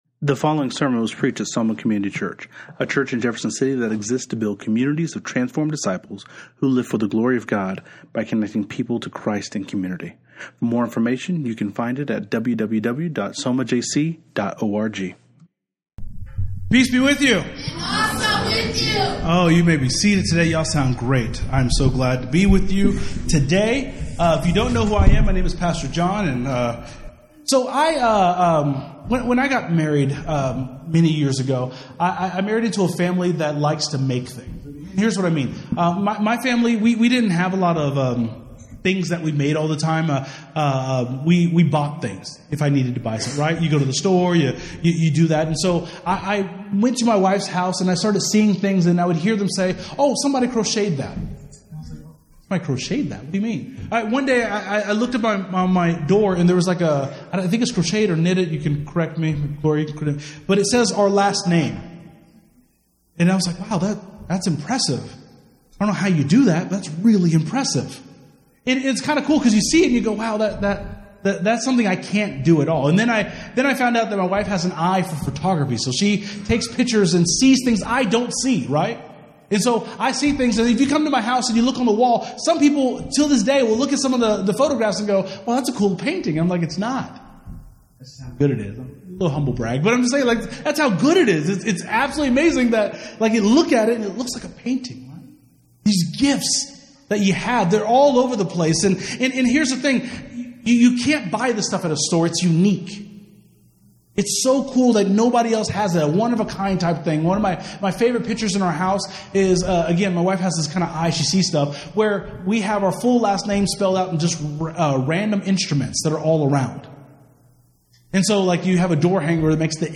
Bible Text: Genesis 2:4-22 | Preacher